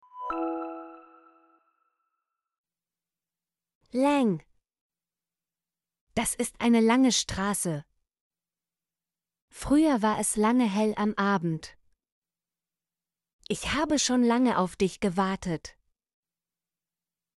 lange - Example Sentences & Pronunciation, German Frequency List